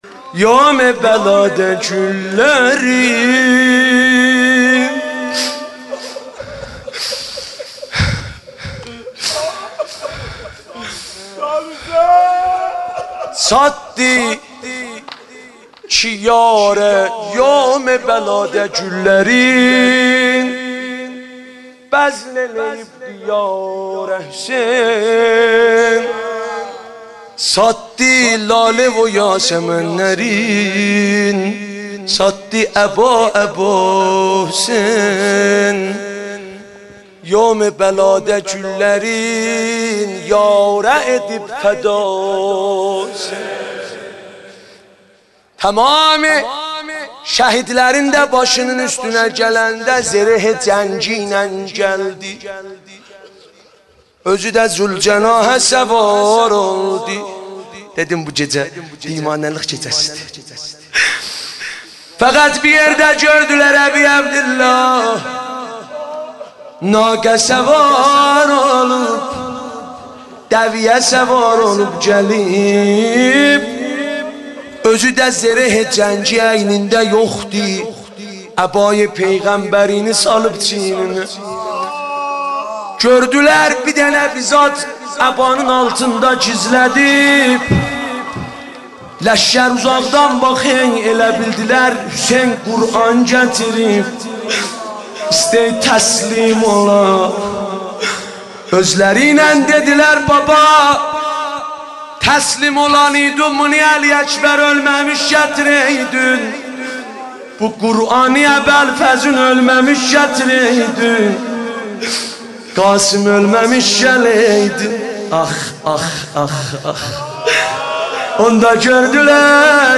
شب هفتم محرم مداحی آذری نوحه ترکی